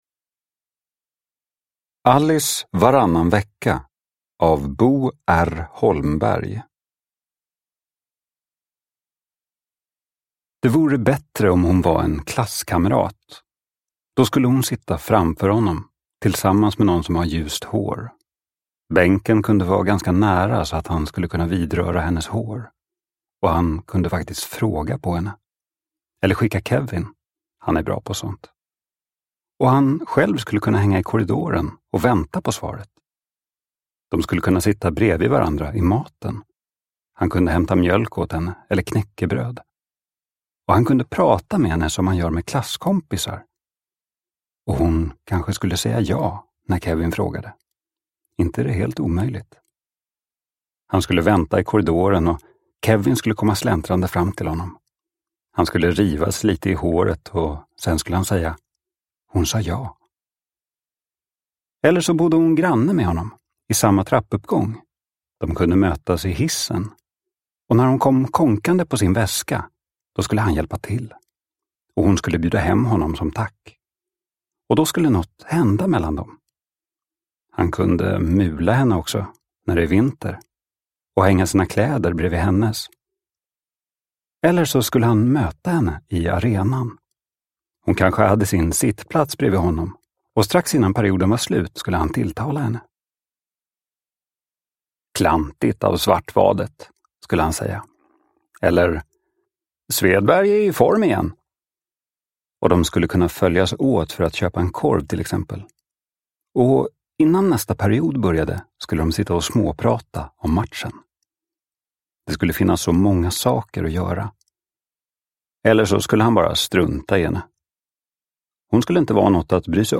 Alice varannan vecka – Ljudbok